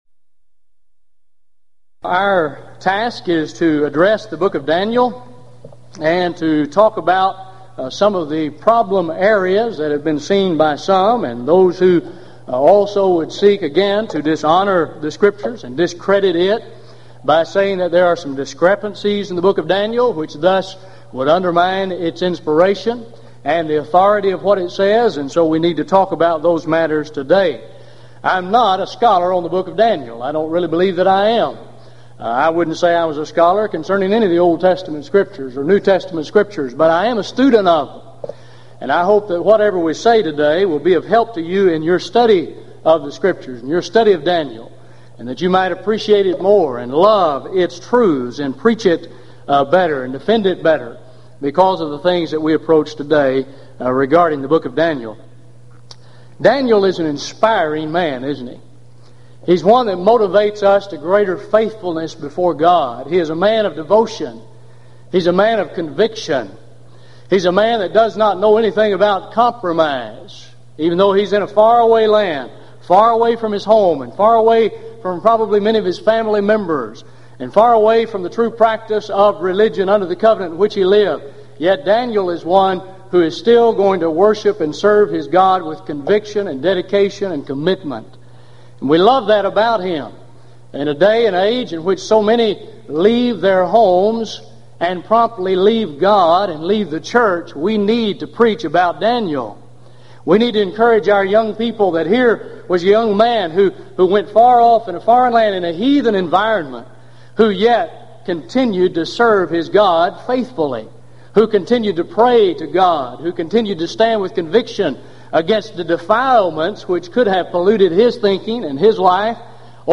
Event: 1995 Gulf Coast Lectures
lecture